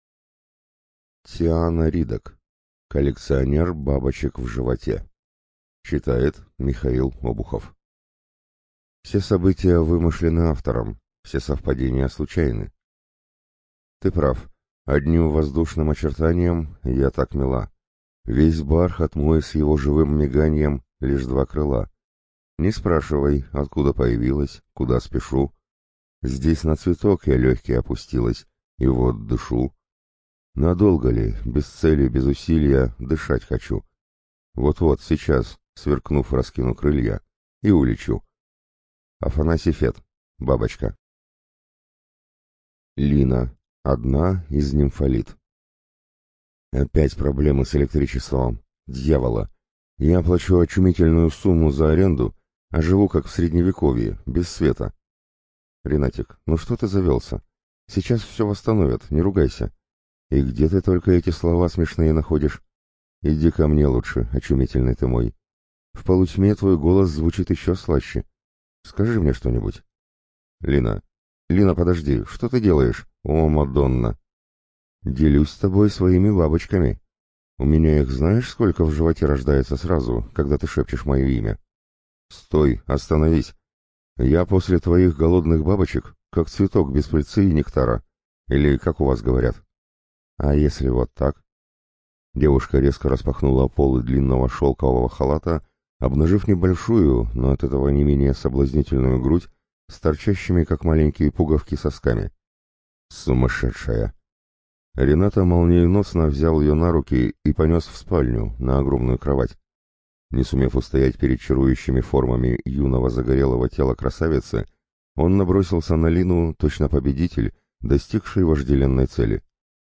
ЖанрЛюбовная проза, Современная проза, Эротика